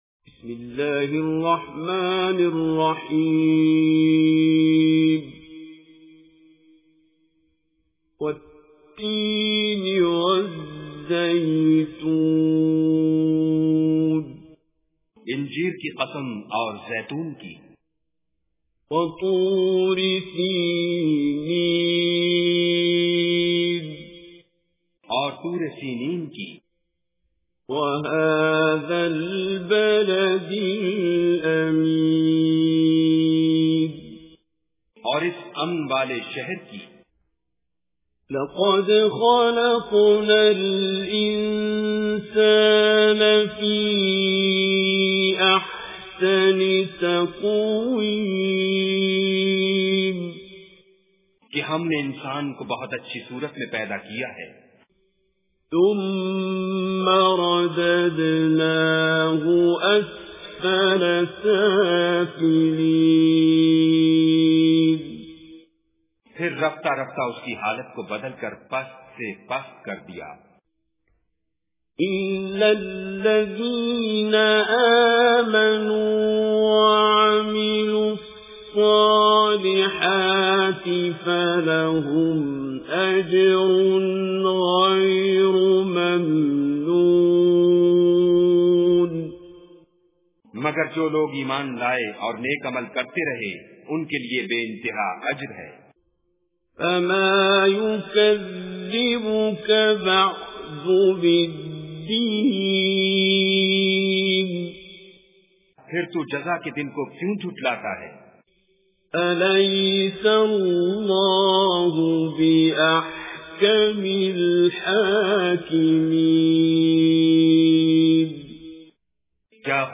Surah Tin Recitation with Urdu Translation
Surah Tin is 95 Surah or chapter of Holy Quran. Listen online and download mp3 tilawat / recitation of Surah Tin in the beautiful voice of Qari Abdul Basit As Samad.